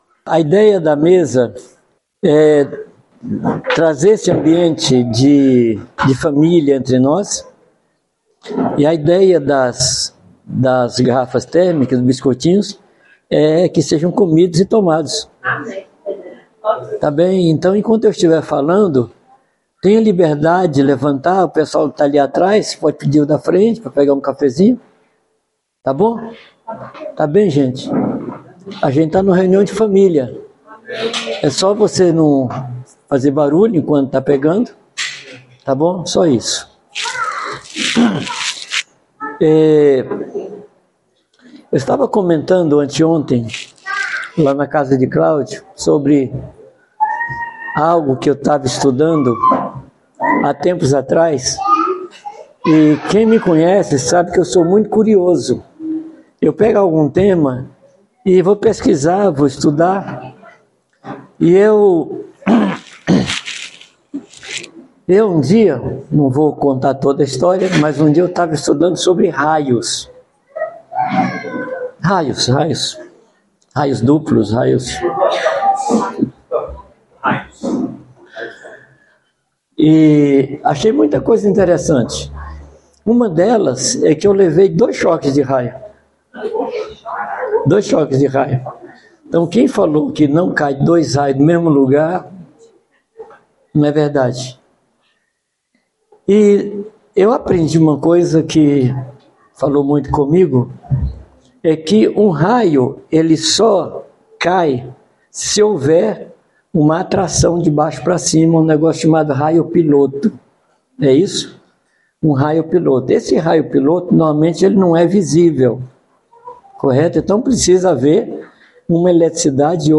Palavra ministrada